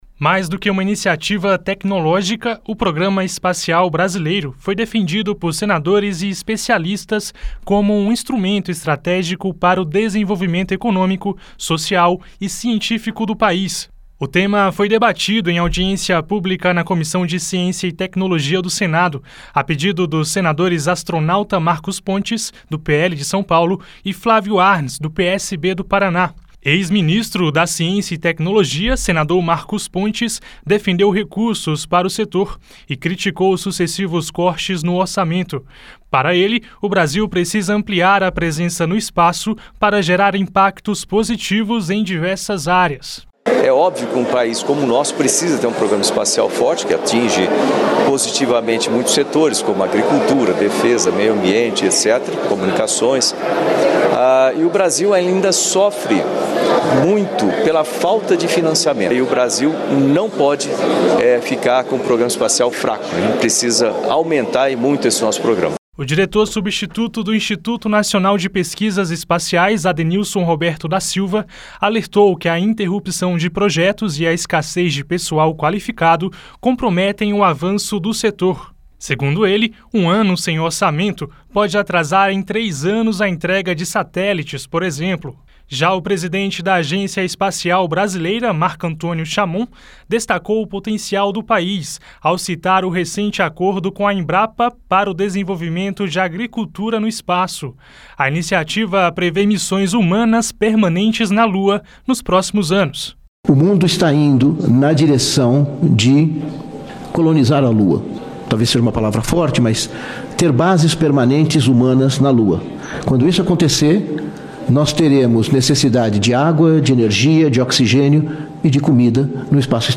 Senadores, especialistas e representantes do setor defenderam o fortalecimento do Programa Espacial Brasileiro durante audiência pública na Comissão de Ciência e Tecnologia (CCT) na quarta-feira (11). Entre os pontos destacados estão o aumento de investimentos, a continuidade de projetos estratégicos e o uso de tecnologias espaciais em políticas públicas. O presidente da Agência Espacial Brasileira, Marco Antonio Chamon, destacou o potencial do país e parcerias com a Embrapa para o desenvolvimento de agricultura no espaço.